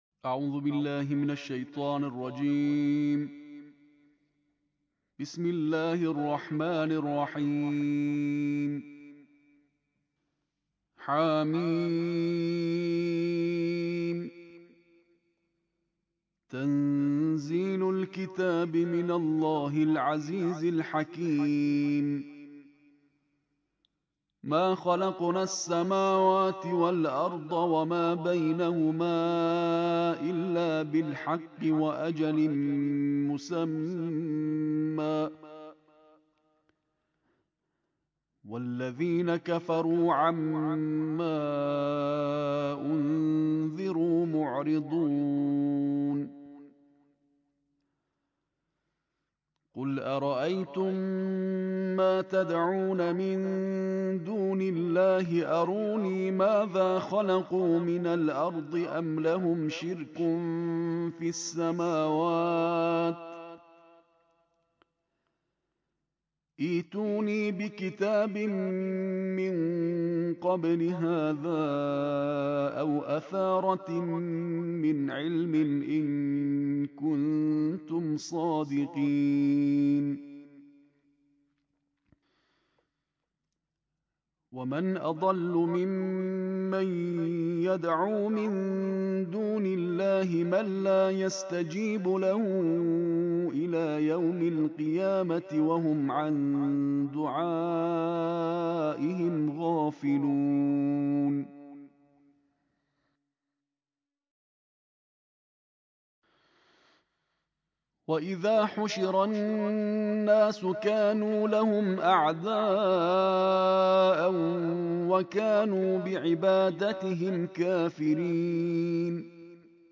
تلاوت ترتیل جزء 26 قرآن کریم با صدای قاری بین‌المللی کشورمان منتشر شده است.
این تلاوت که برای اولین بار منتشر می‌شود سال‌های ۱۳۹۹ و ۱۴۰۰ در استودیوی شورای عالی قرآن ضبط شده و سال ۱۴۰۱ در حاشیه هفدهمین نشست تخصصی استادان، قاریان و حافظان ممتاز قرآن با حضور وزیر فرهنگ و ارشاد اسلامی رونمایی شده است.
از ویژگی‌های این تلاوت باید به کیفیت بالای صوت قاری و کیفیت فنی ضبط آن اشاره کرد.